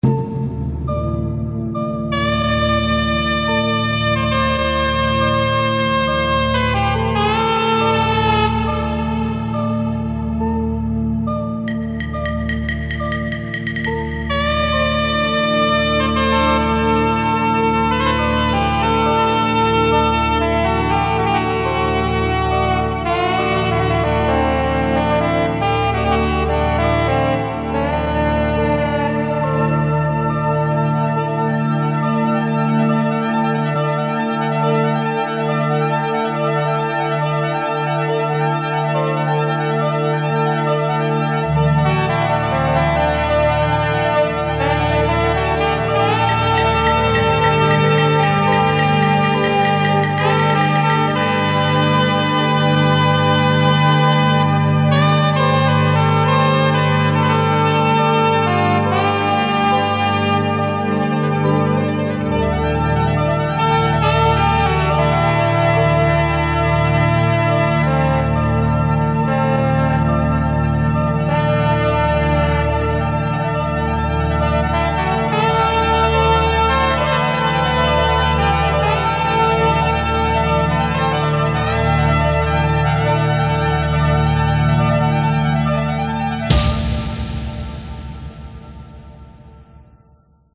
Slow, Celtic atmospheric track